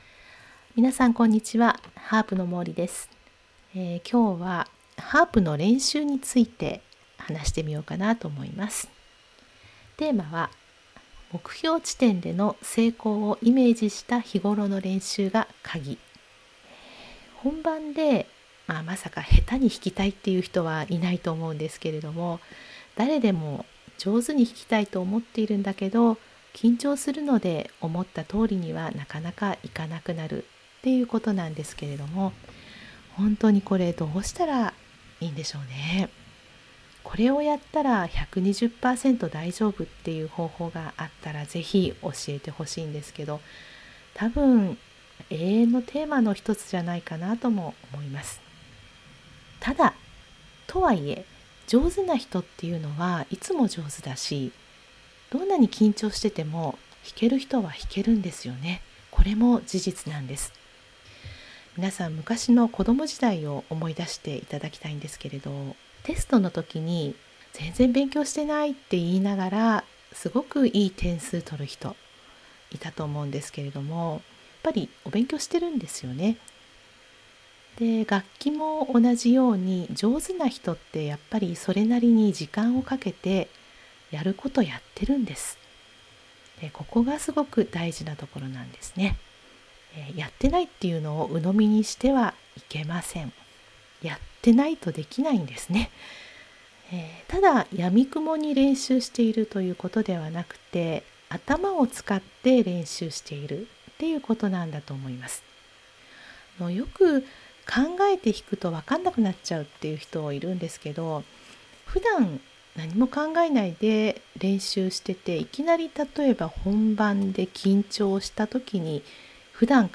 （音声ブログ）目標地点での成功をイメージした日頃の練習が鍵